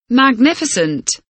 magnificent kelimesinin anlamı, resimli anlatımı ve sesli okunuşu